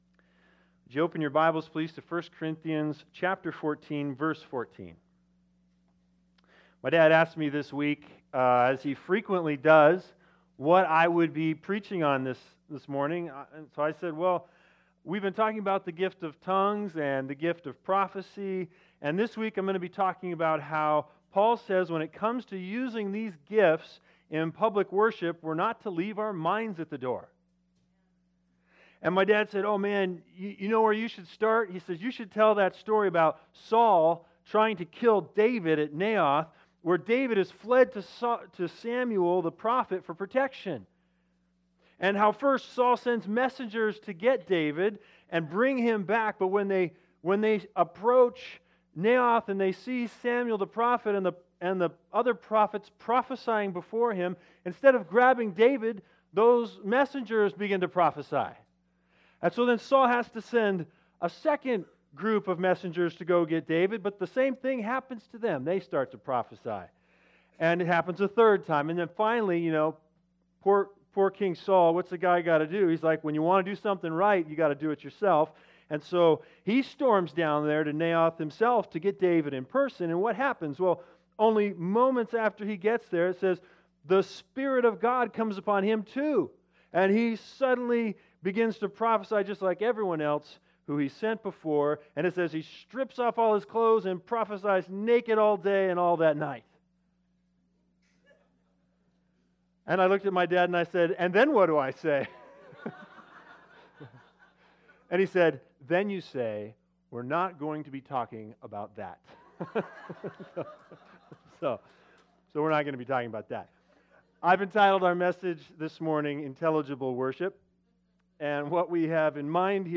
Big Idea: Don’t check your mind at the church door. Listen to part 48 of our 1 Corinthians sermon series to learn what intelligible worship means and its result.